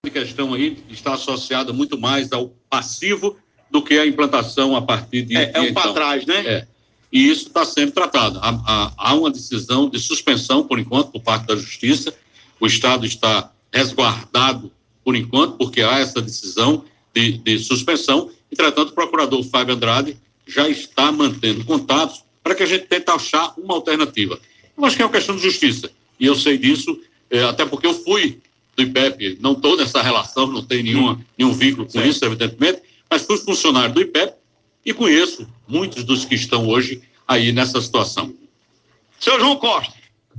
A surpresa maior é por conta uma entrevista do governador à Correio FM.
Áudio-Caso-Ipep-João-dizendo-ser-caso-de-justiça.mp3